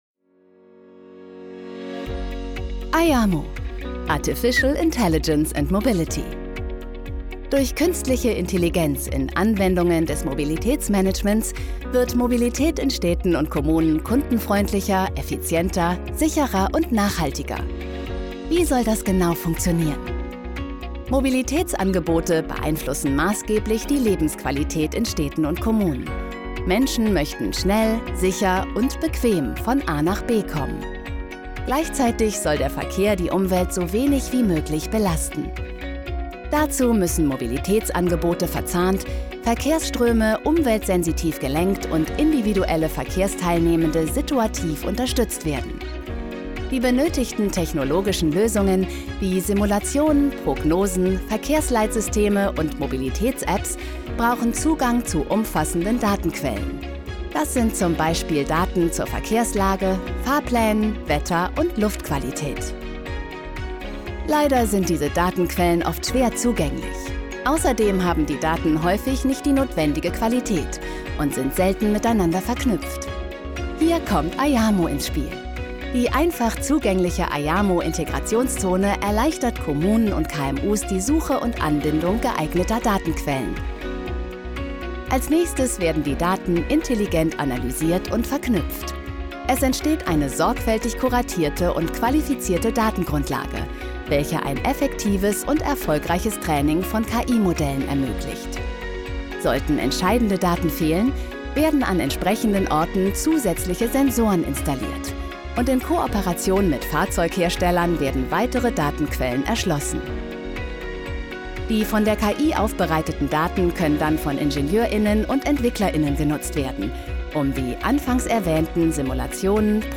Commerciale, Accessible, Chaude, Douce, Corporative
Corporate